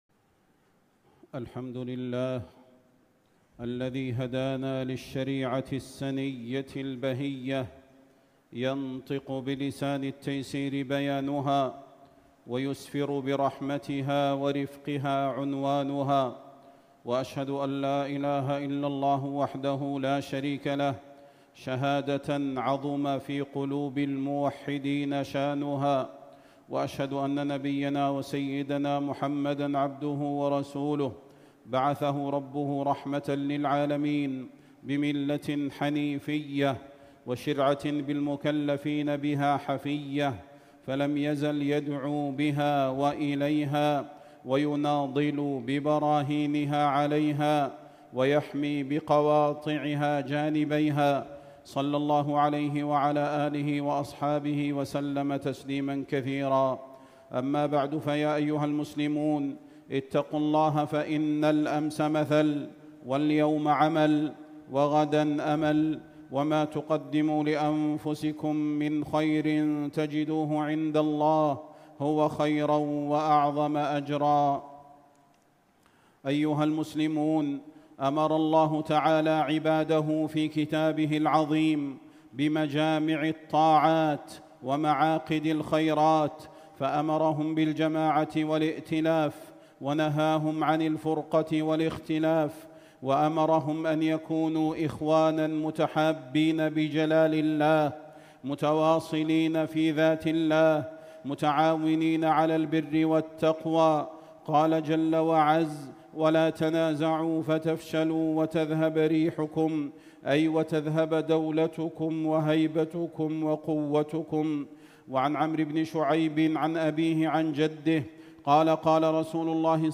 خطبة الجمعة 27 شوال 1446هـ بمسجد السلطان محمد الأعظم بجمهورية المالديف > تلاوات و جهود الشيخ صلاح البدير > تلاوات وجهود أئمة الحرم النبوي خارج الحرم > المزيد - تلاوات الحرمين